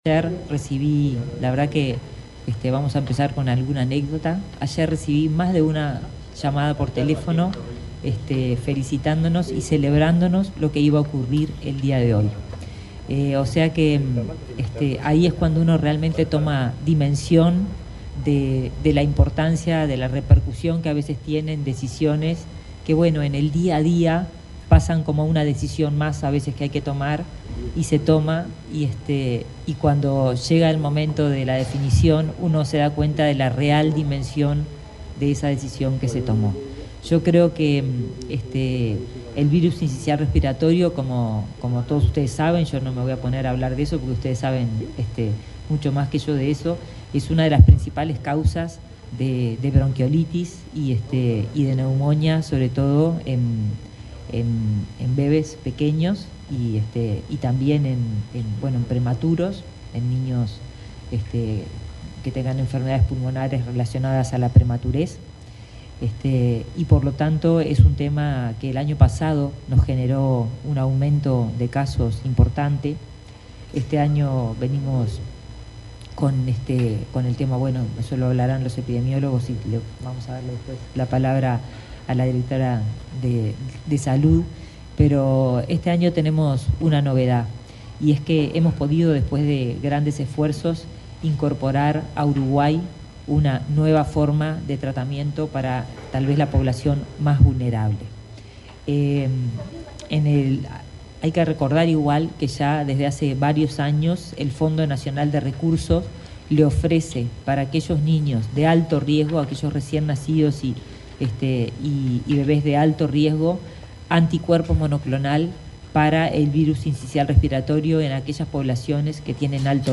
Palabras de autoridades en acto en el MSP
Palabras de autoridades en acto en el MSP 15/08/2024 Compartir Facebook X Copiar enlace WhatsApp LinkedIn Este jueves 15 en Montevideo, la titular del Ministerio de Salud Pública (MSP), Karina Rando, y el subsecretario de la cartera, José Luis Satdjian, participaron en el acto de lanzamiento de una campaña de vacunación contra el virus respiratorio sincicial.